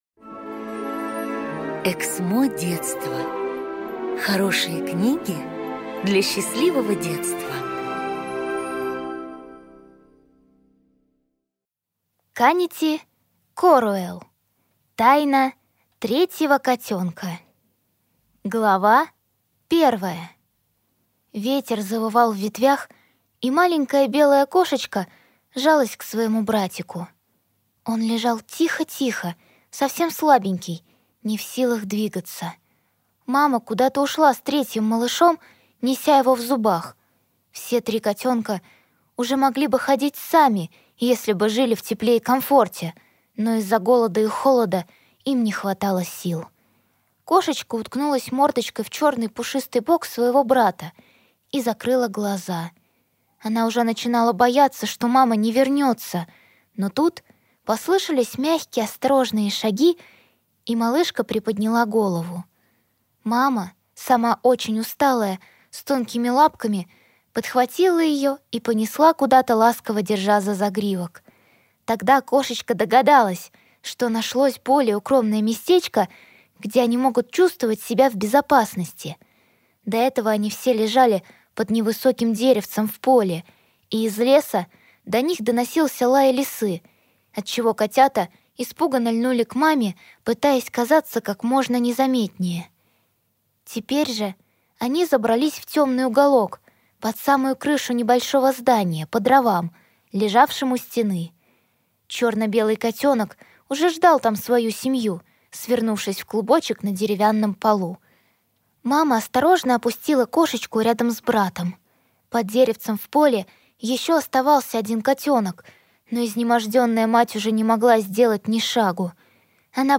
Аудиокнига Тайна третьего котёнка | Библиотека аудиокниг
Прослушать и бесплатно скачать фрагмент аудиокниги